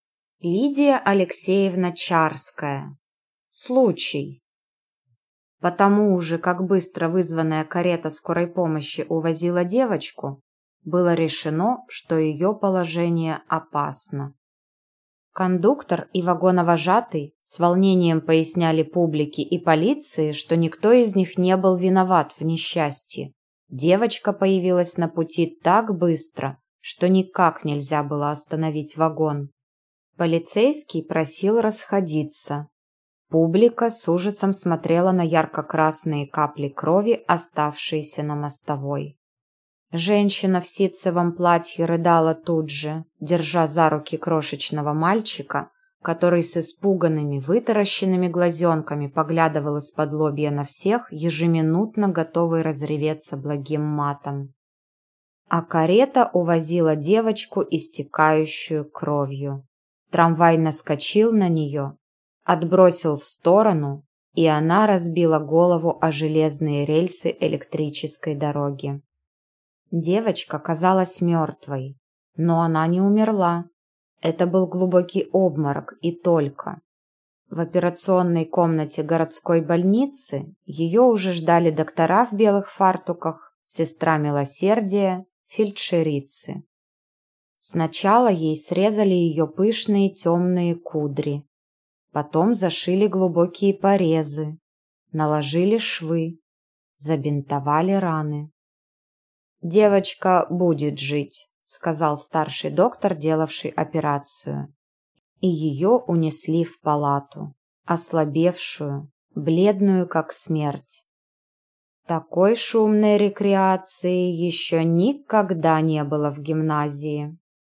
Аудиокнига Случай | Библиотека аудиокниг